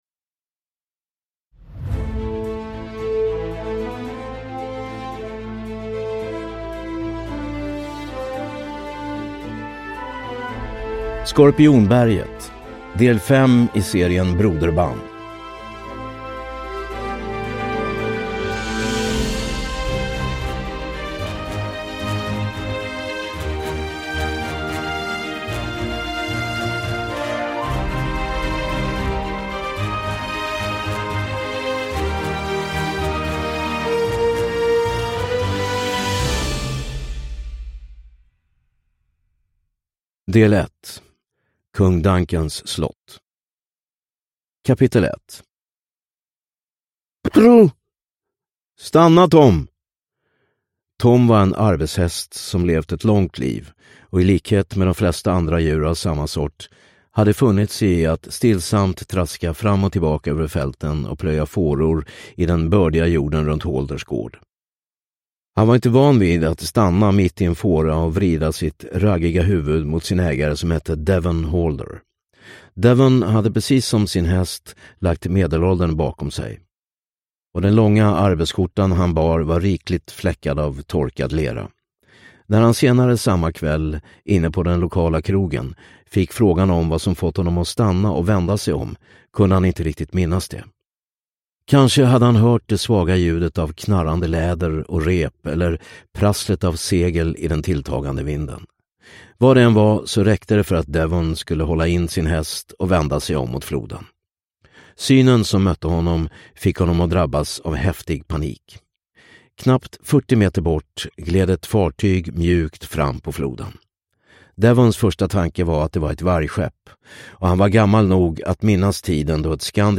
Skorpionberget – Ljudbok